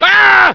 scream09.ogg